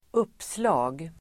Uttal: [²'up:sla:g]